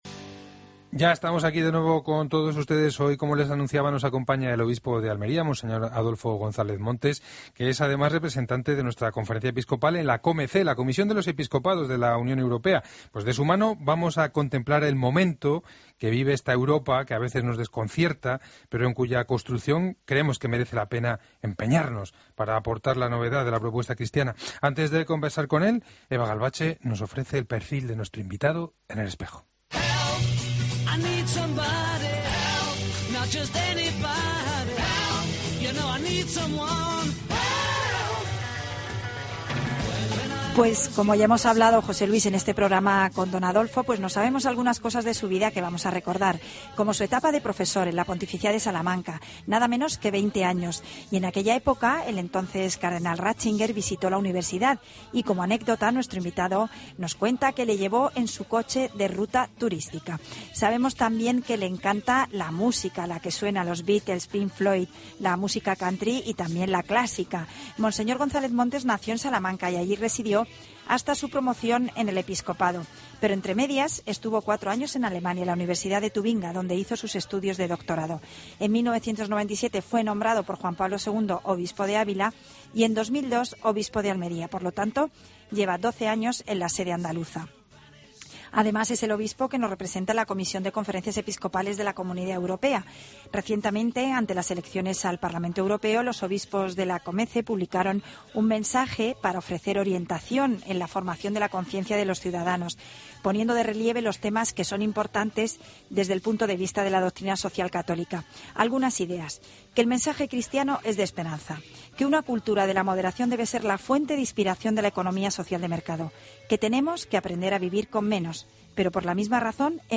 AUDIO: Escucha la entrevista completa a monseñor González Montes en 'El Espejo'